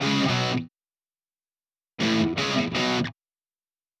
Power Pop Punk Guitar Ending.wav